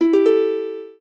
lyre_ega.ogg